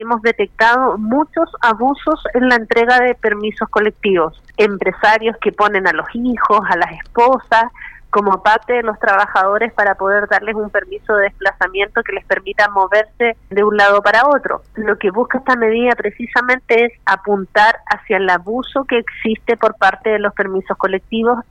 La Gobernadora explicó que se debió aplicar esta iniciativa por el abuso de algunos empresarios en la entrega de los permisos colectivos.